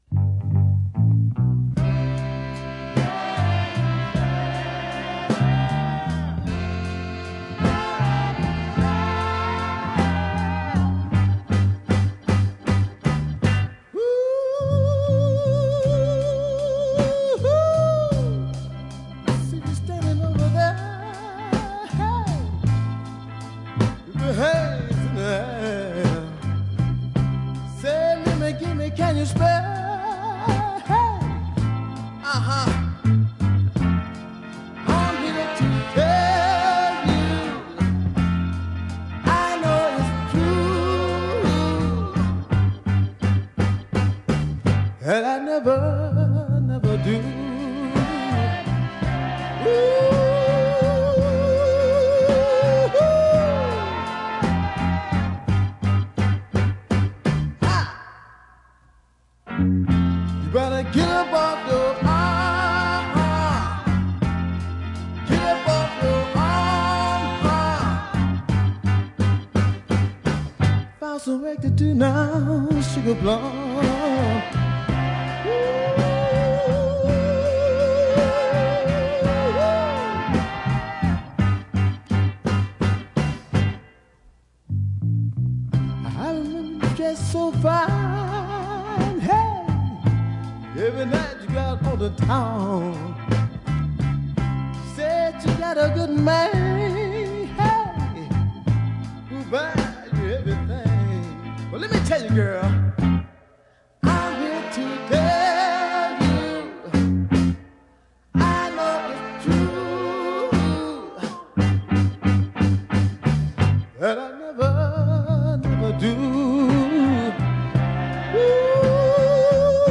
a strong deep soul ballad
Lovely vocal harmonies
featuring some very tasty falsetto phrases.